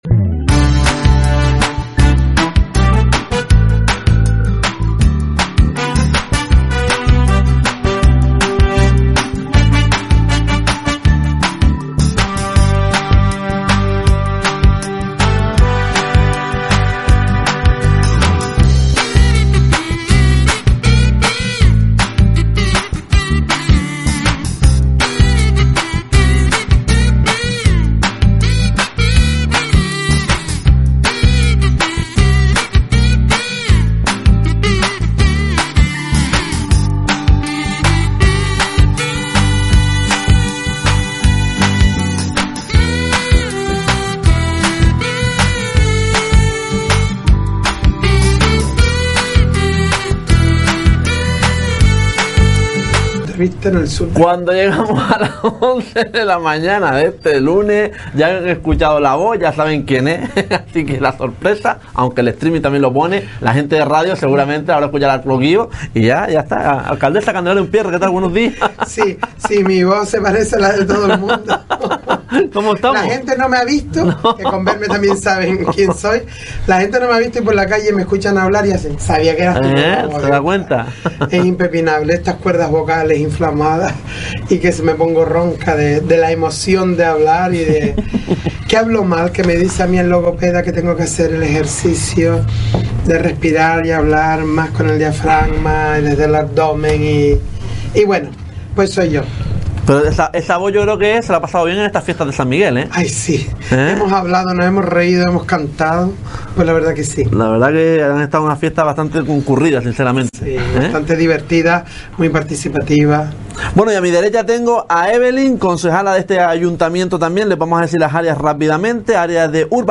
Entrevistamos a la Alcaldesa de Tuineje, Candelaria Umpiérrez, y a los concejales Evelyn Ávila y Pedro Rodríguez.